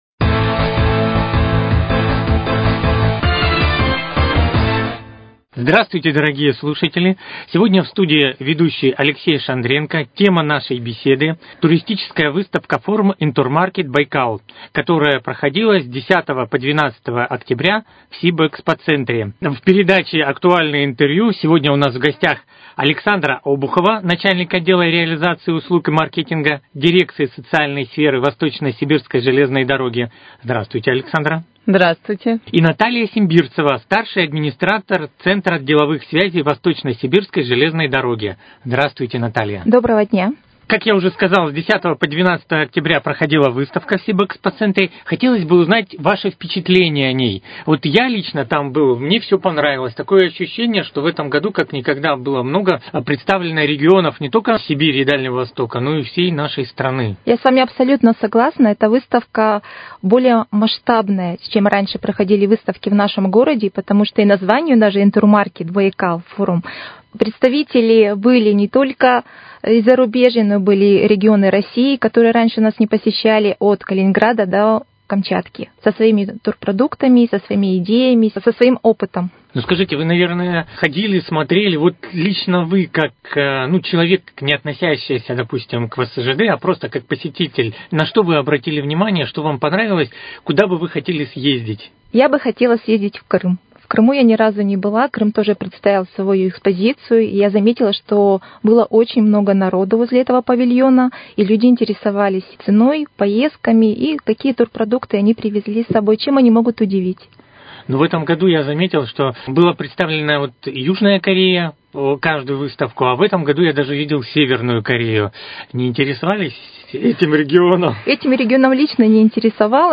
Актуальное интервью: Беседа с участниками выставки-форума «Интурмаркет.Байкал»